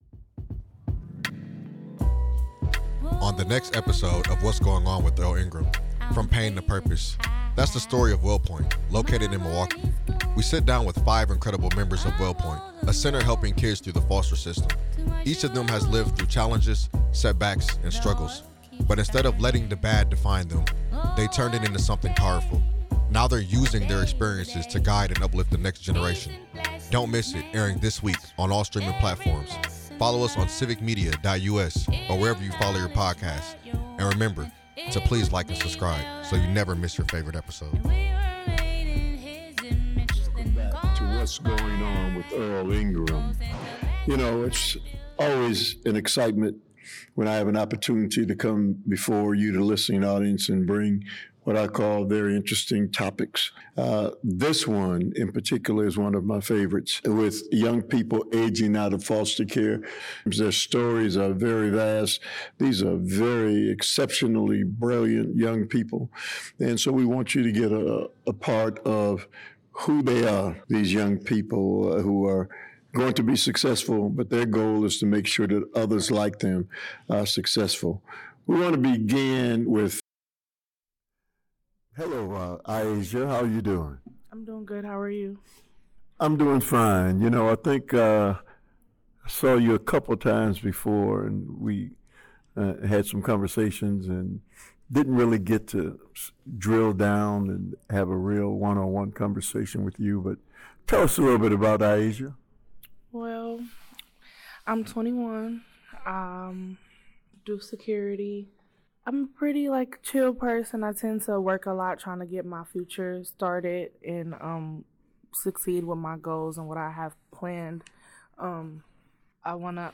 Right here in Milwaukee, we sit down with five incredible members of Wellpoint, a center helping kids through the foster system.